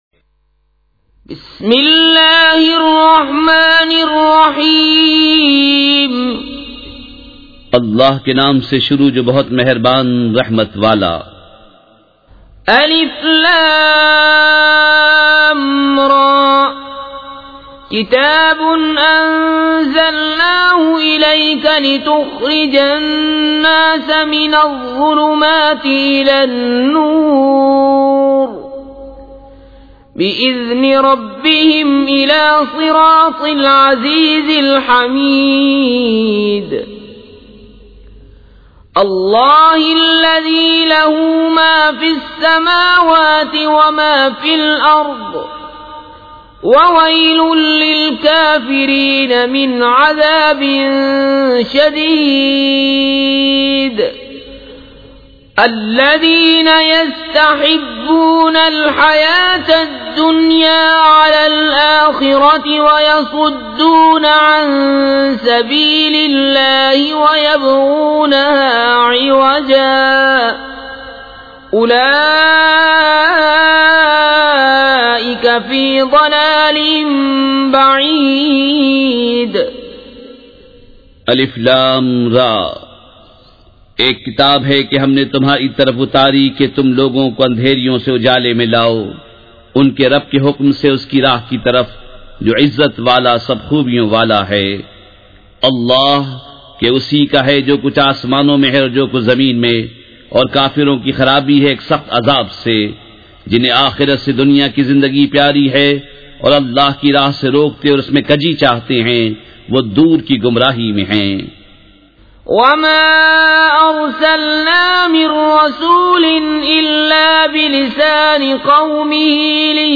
سورۃ ابراہیم مع ترجمہ کنزالایمان ZiaeTaiba Audio میڈیا کی معلومات نام سورۃ ابراہیم مع ترجمہ کنزالایمان موضوع تلاوت آواز دیگر زبان عربی کل نتائج 2074 قسم آڈیو ڈاؤن لوڈ MP 3 ڈاؤن لوڈ MP 4 متعلقہ تجویزوآراء